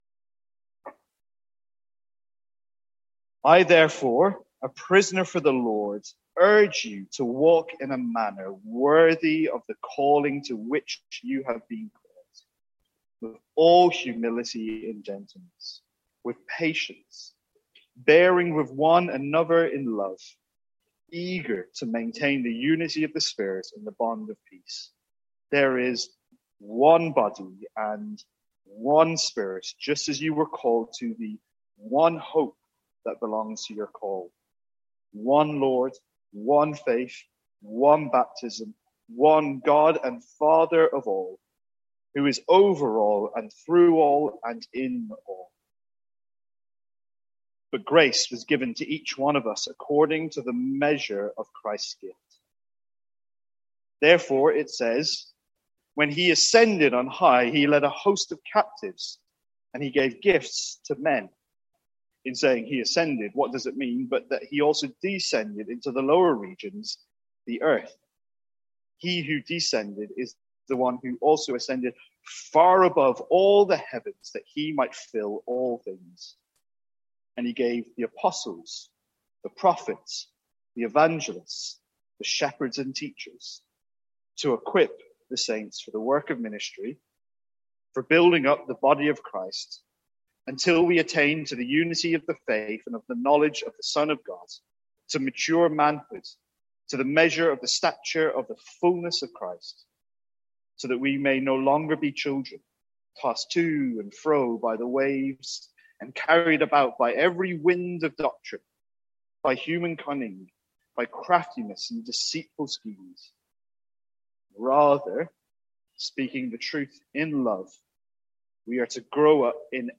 Sermons | St Andrews Free Church
From our morning series in Ephesians.